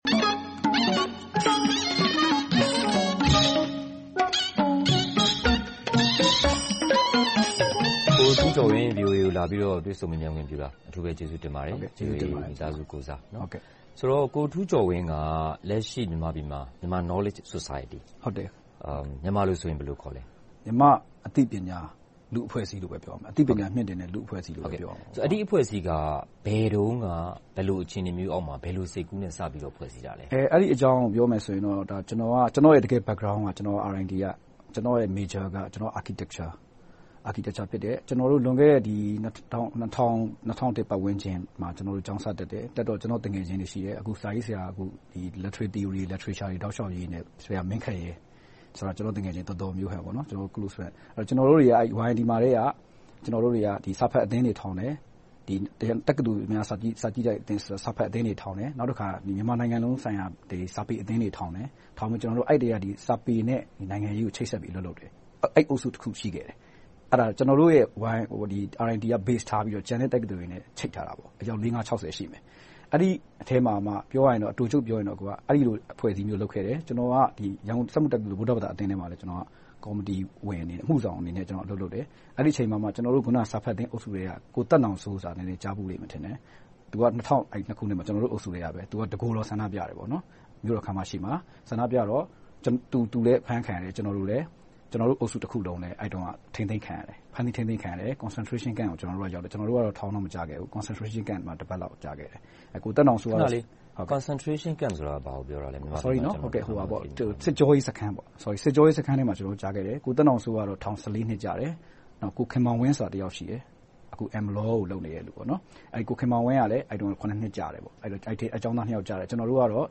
တွေ့ဆုံမေးမြန်းခန်း